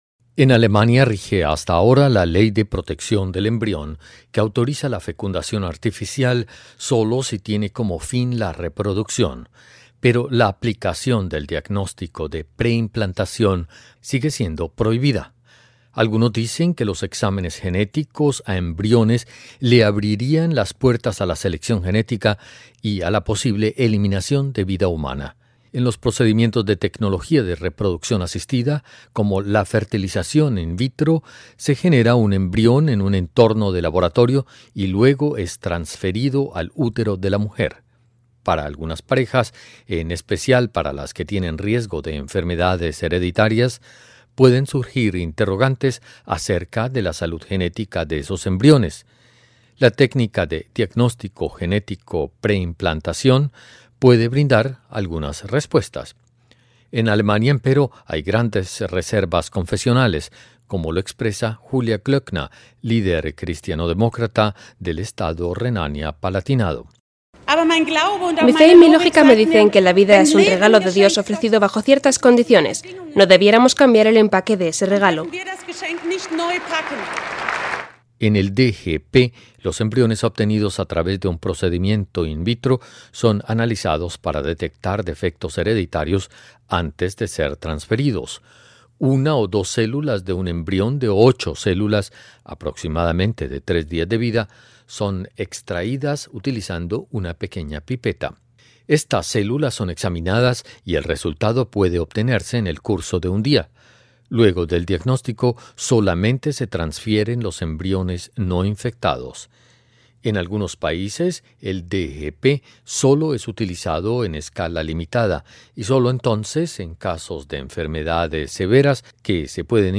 El llamado diagnóstico genético de pre implantación revivió en Alemania un debate que lleva años sin que se llegue a una decisión definitiva. Escuche el informe de la radio Deutsche Welle.